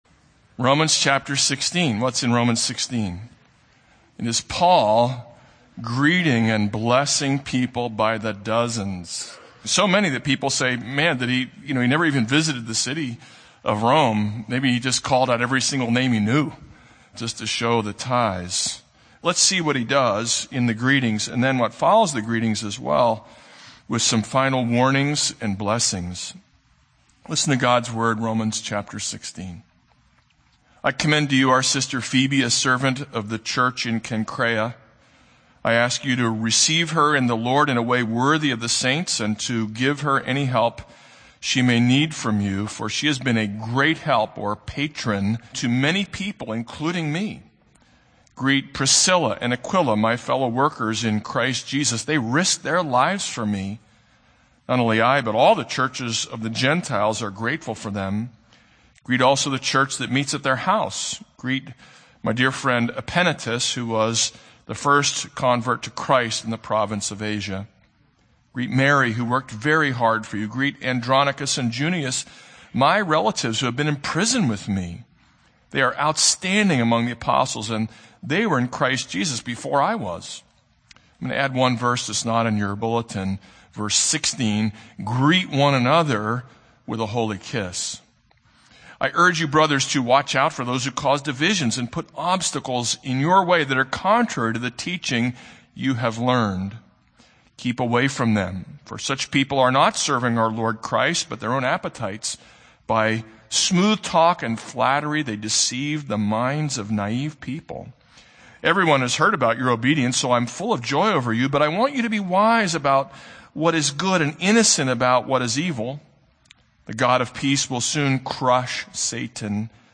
This is a sermon on Romans 16:1-27.